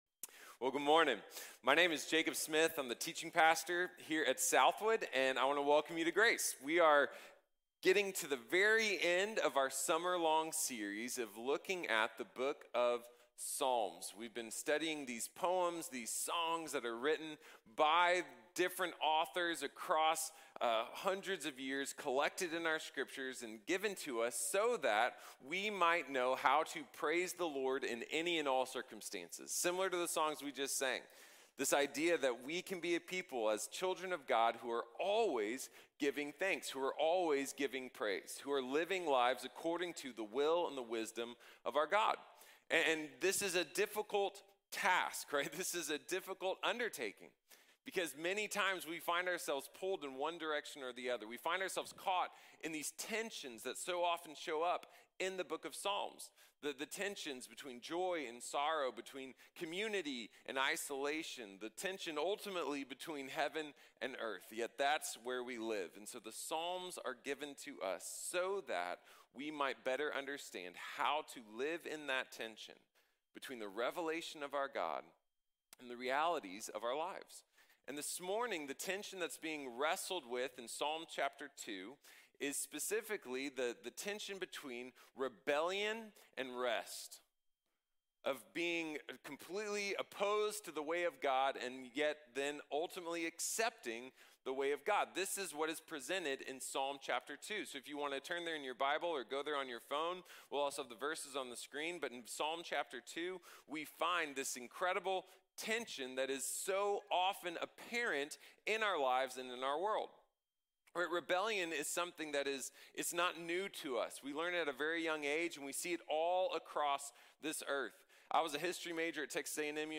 God Reigns | Sermon | Grace Bible Church